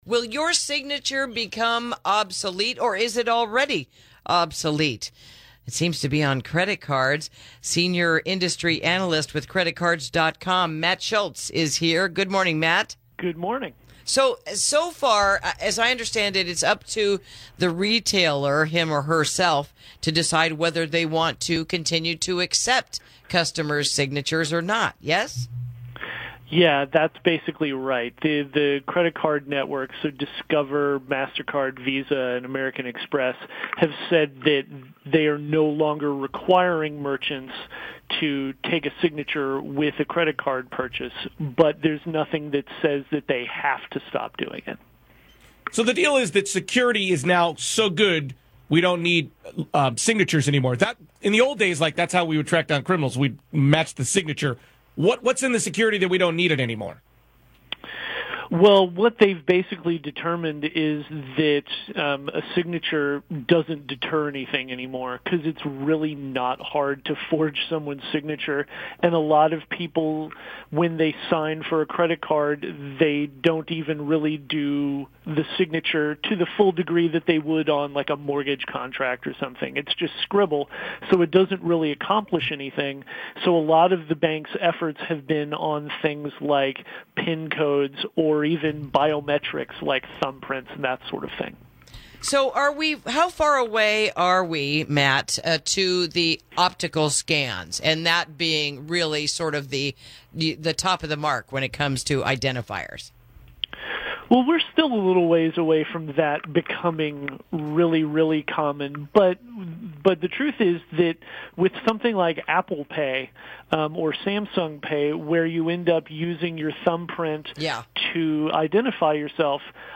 Interview: Credit Card Signatures, Writing Checks Becoming Obsolete As Technology Moves Forward